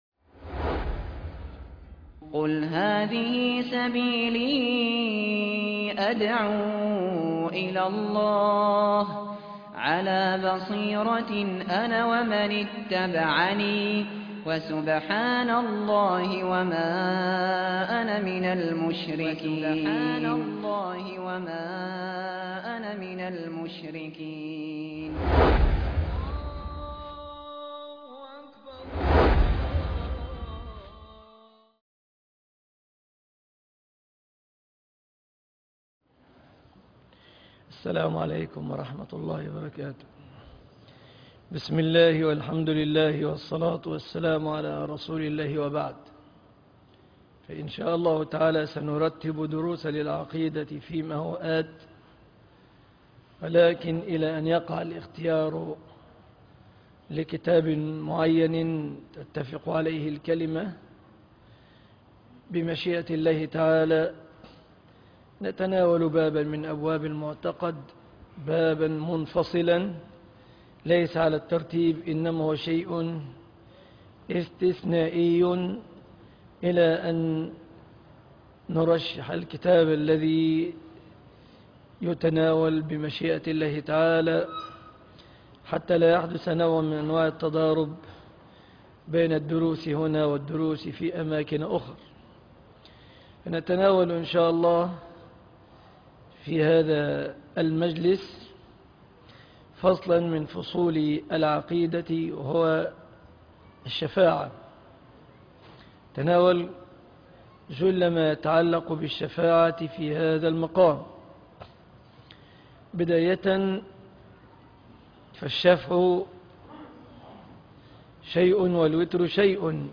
دروس العقيدة - مجمع التوحيد بالمنصورة